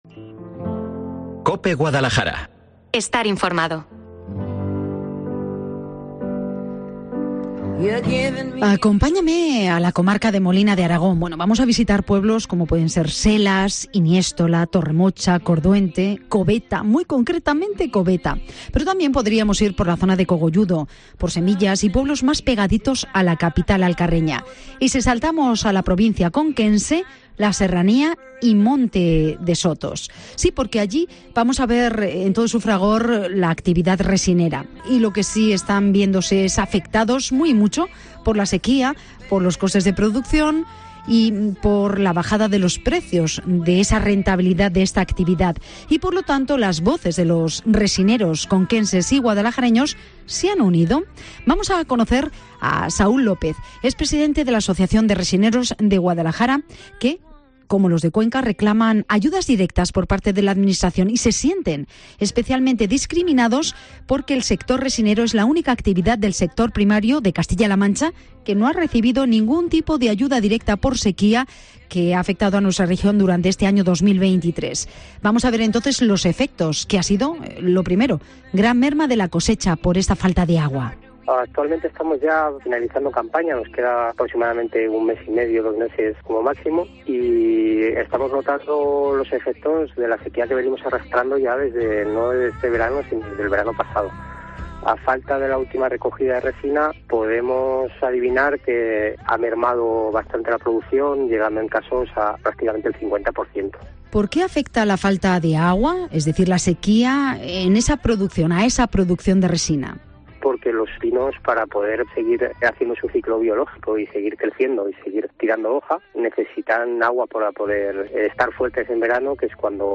Guadalajara